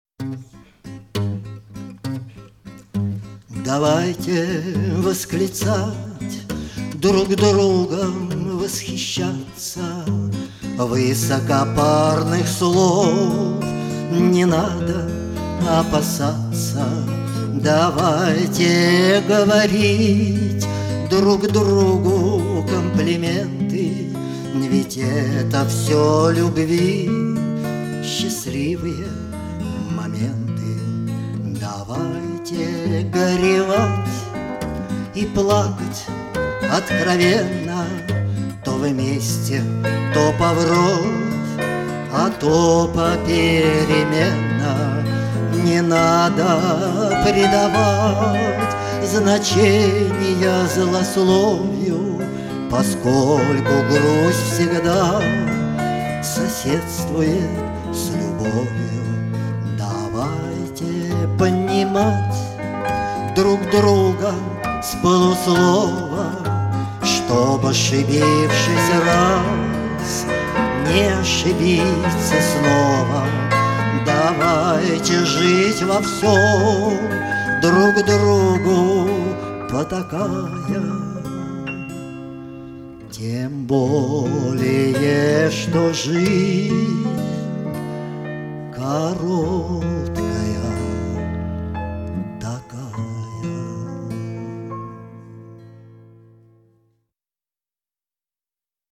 Бардовские песни